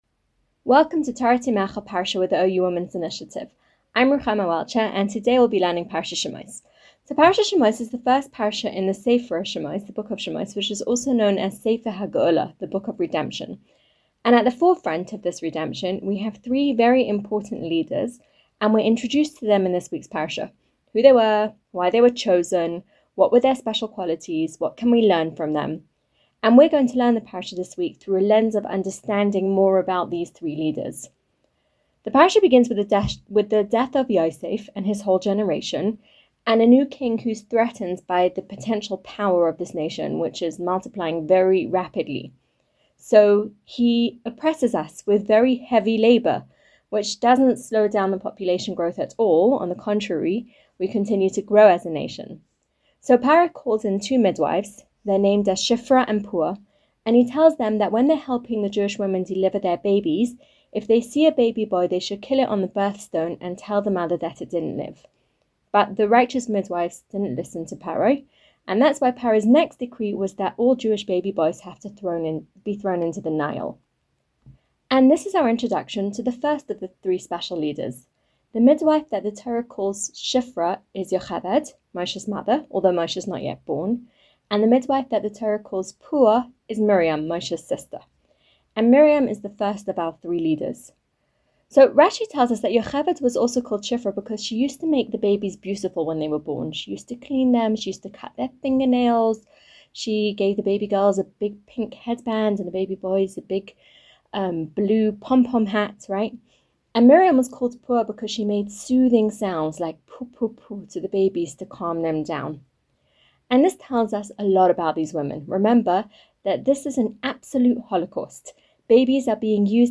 In this new parsha series, women scholars will deliver a weekly ten- to fifteen-minute shiur on the weekly parsha. Shiurim are geared toward learners of all levels.